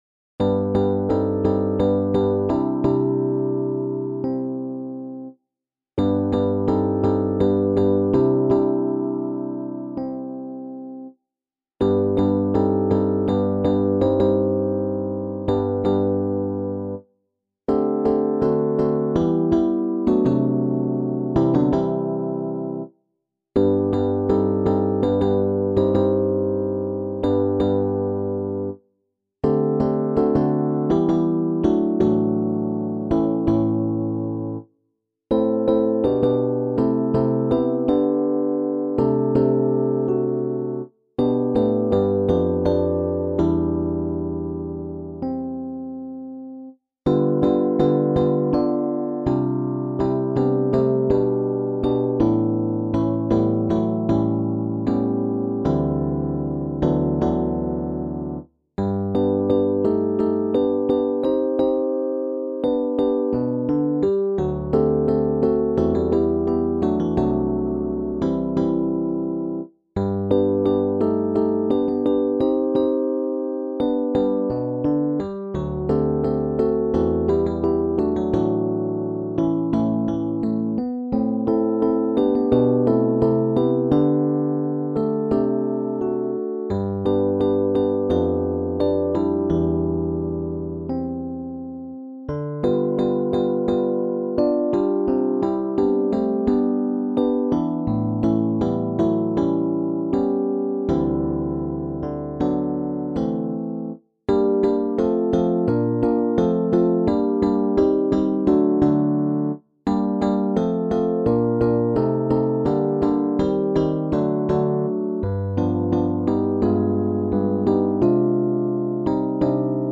SSATTB | SATB | AATTBB | SSATB | SATTB